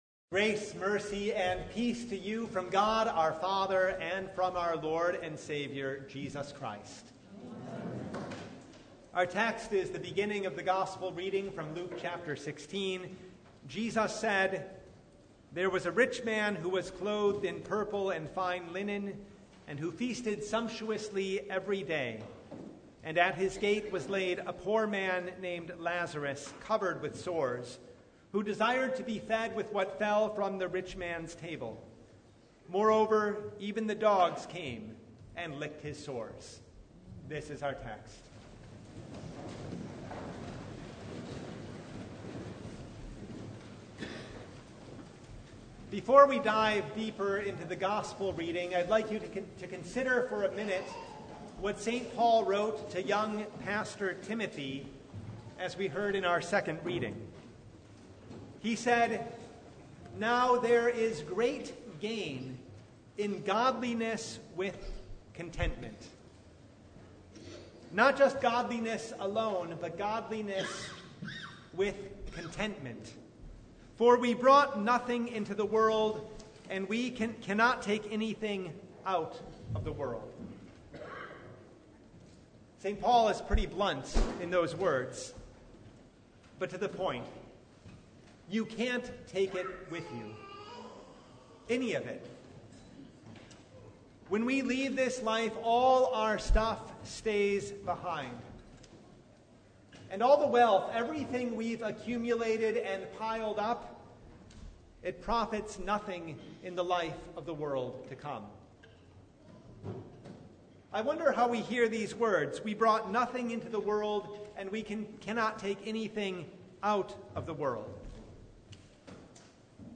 Luke 16:19–31 Service Type: Sunday You can’t take it with you.